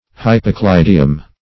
Search Result for " hypocleidium" : The Collaborative International Dictionary of English v.0.48: Hypocleidium \Hy`po*clei"di*um\, n.; pl.